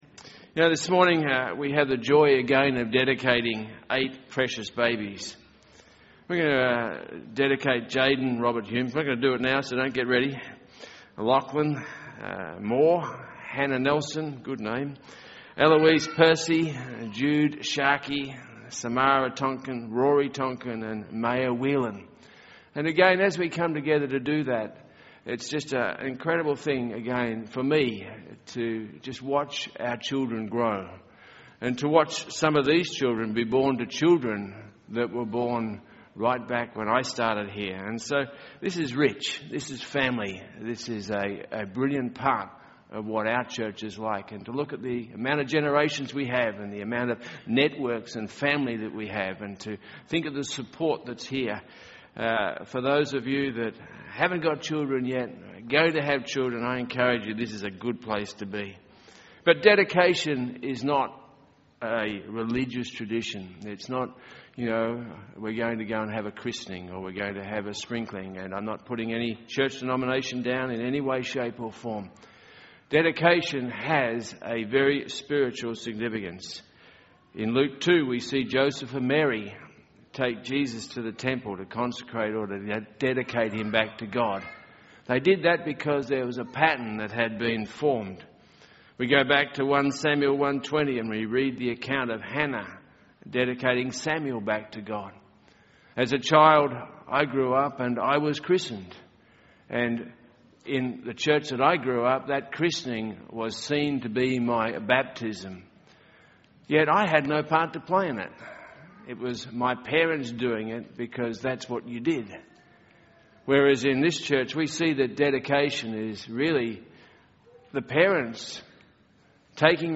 Dedication Service: The Apple Seed
During this service, eight families dedicated their little ones.